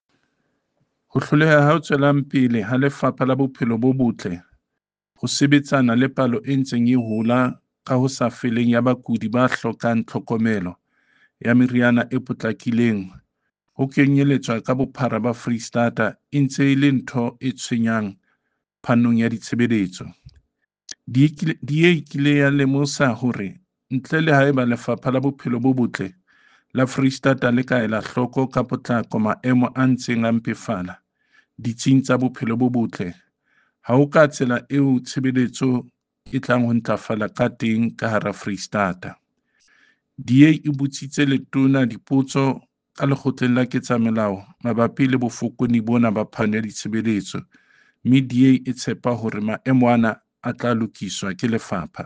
Sesotho soundbites by David Masoeu MPL and Afrikaans soundbite by Werner Pretorius MPL